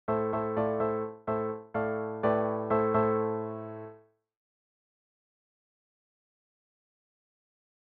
To give you an idea of the effect we’re talking about, here are some simple short melodic fragments, first given in a major key, and then in a minor key equivalent:
Am G/A  Am  Dm  G/A  A (
The minor mode gives the music a darker mood, with potential for a bit more edge. These are just midi files that I’ve posted here, so you’ll have to use your imagination and your own instrumentation to bring them to life.